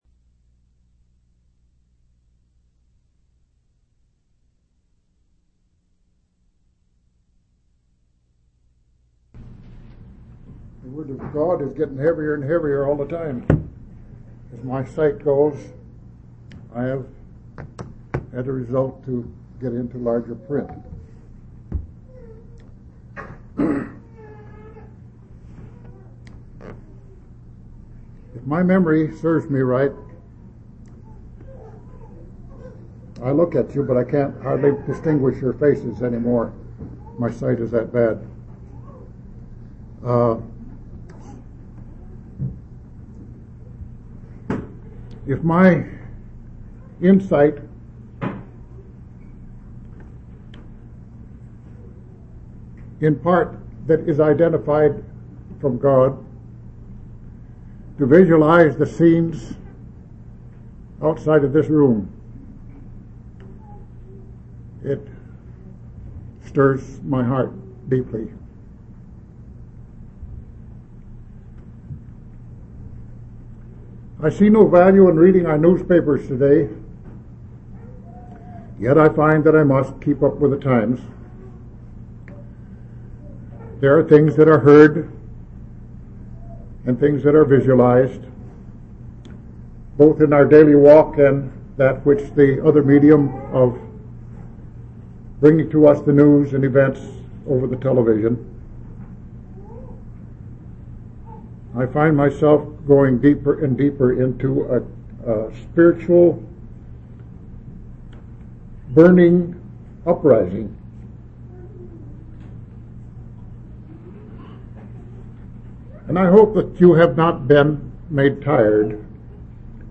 audio-sermons